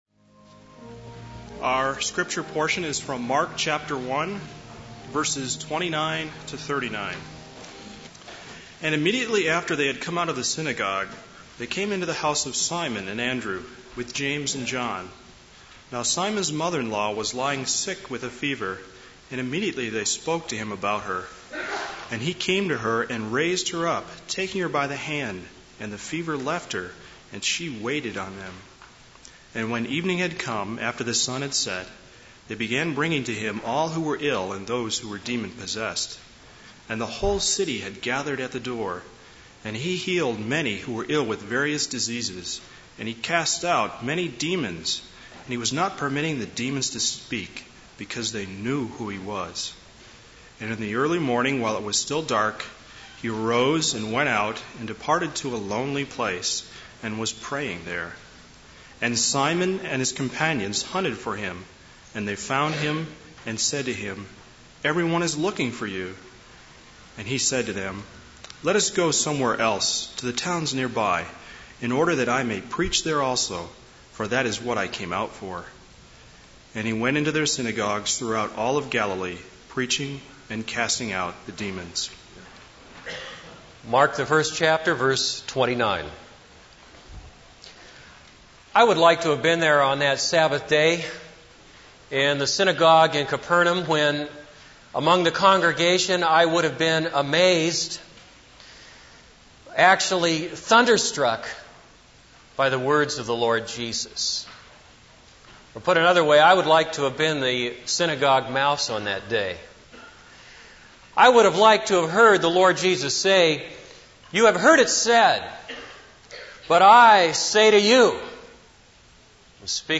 This is a sermon on Mark 1:29-39.